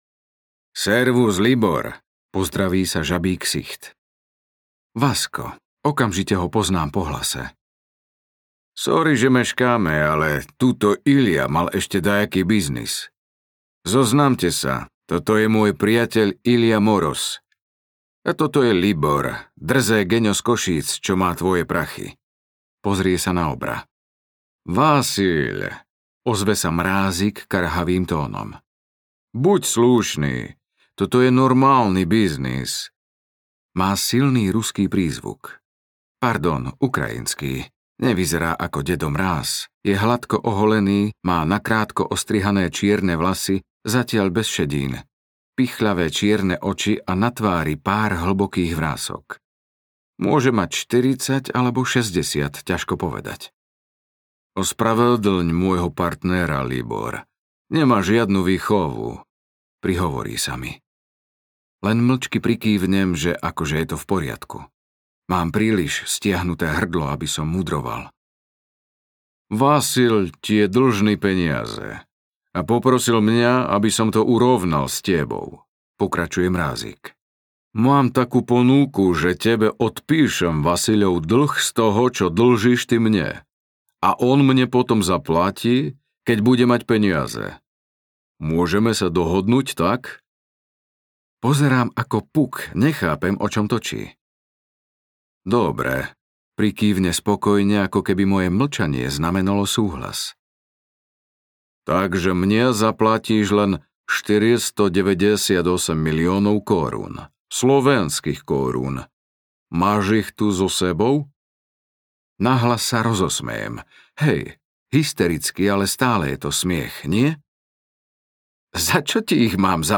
Ukázka z knihy
zlodej-audiokniha